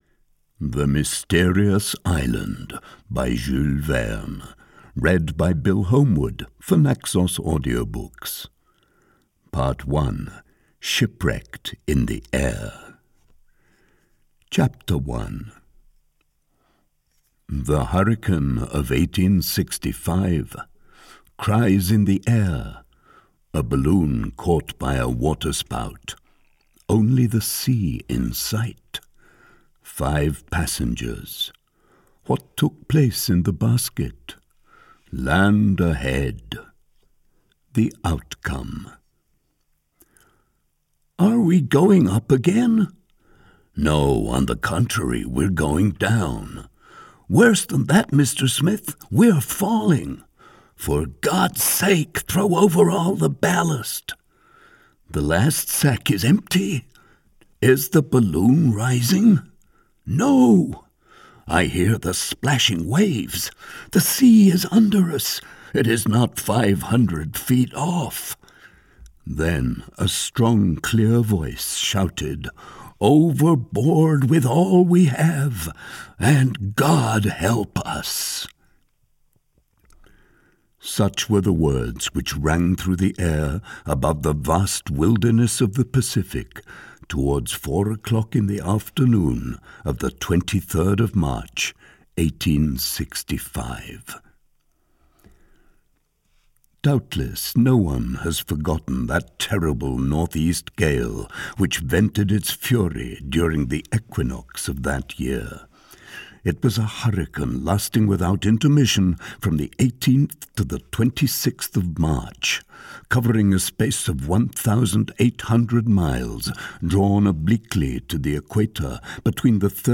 The Mysterious Island audiokniha
Ukázka z knihy